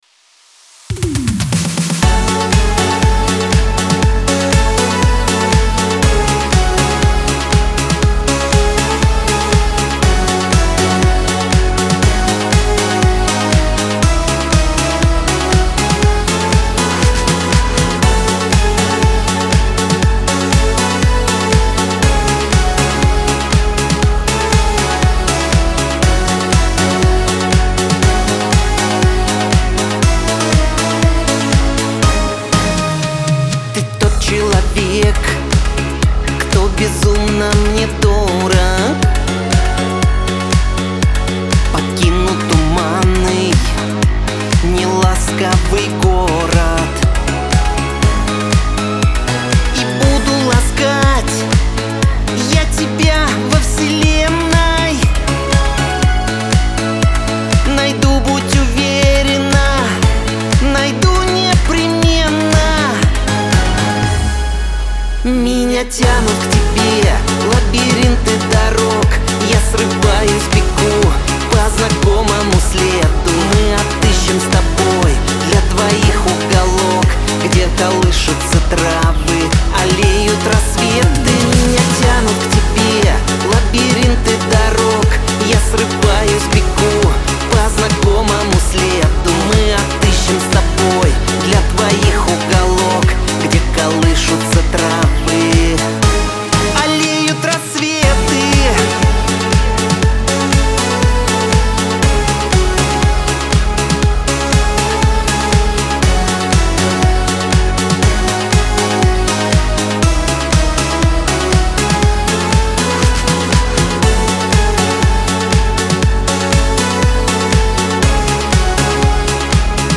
эстрада , pop